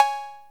TR808CB0.WAV